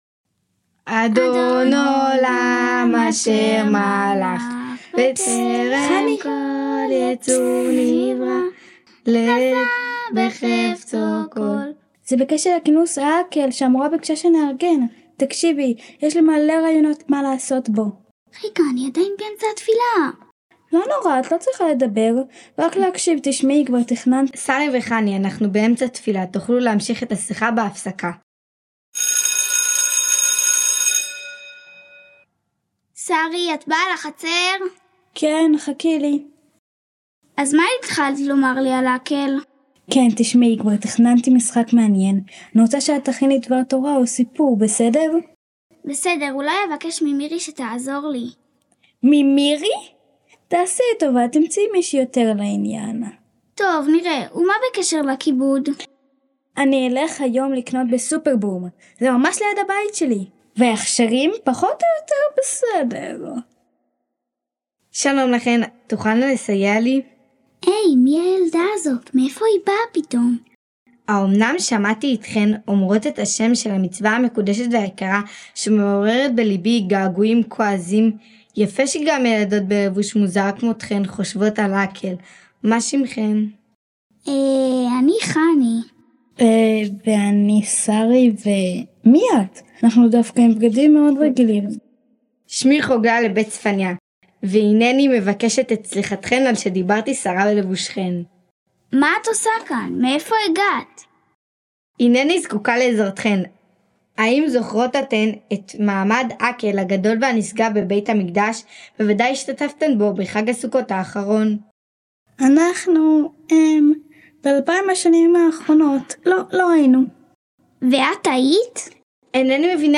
פס קול הצגה - בתי ספר